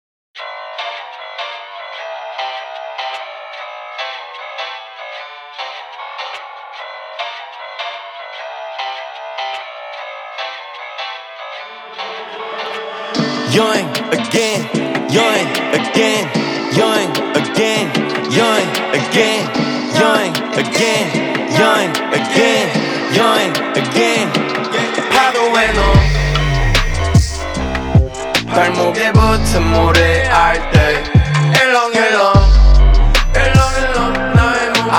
Жанр: Рэп и хип-хоп / Альтернатива
# Alternative Rap